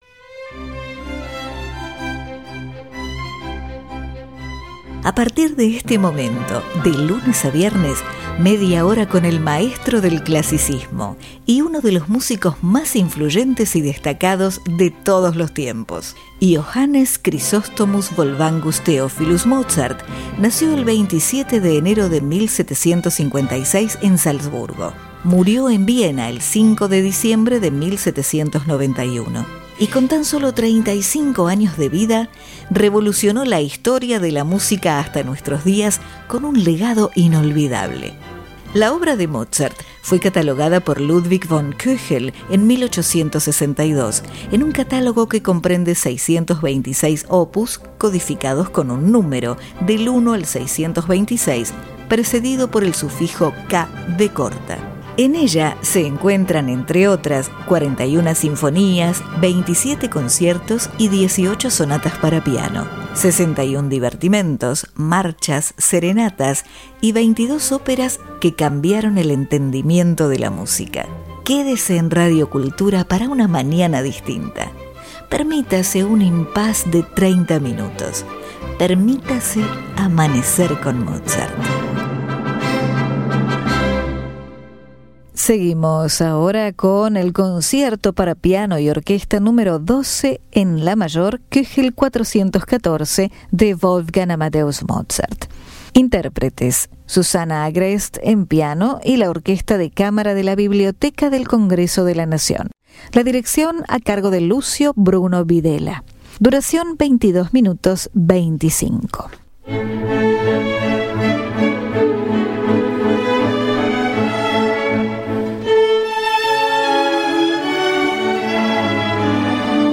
Concierto Para Piano Y Orquesta Nº 12 En La Mayor K. 414
Orquesta De Camara De La Biblioteca Del Congreso De La Nacion